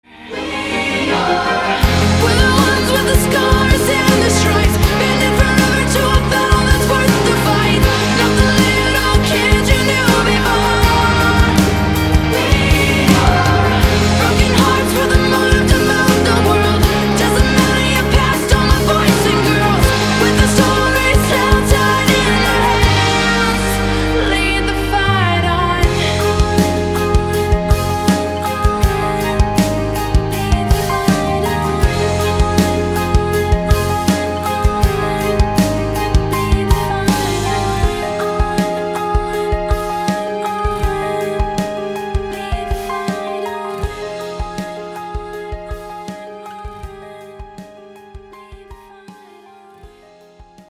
поп
красивые
женский вокал
пианино
alternative
поп-рок
инди рок